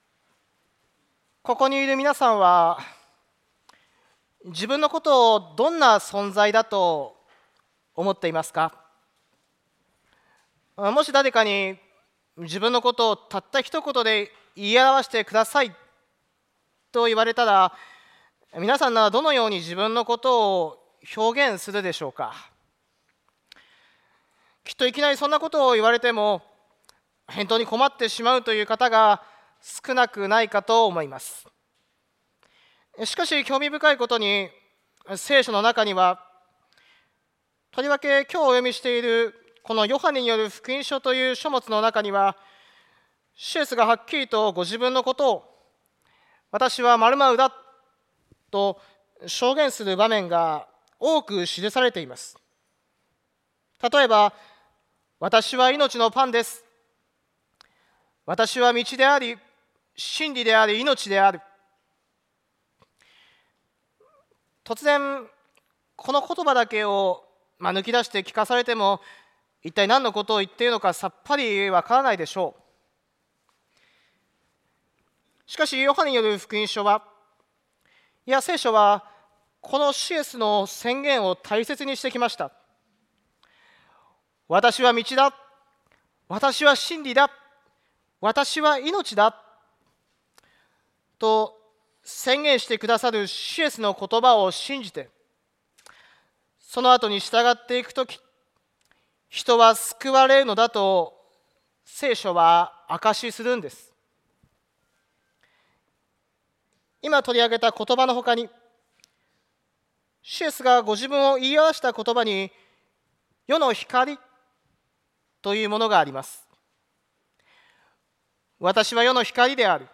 主日礼拝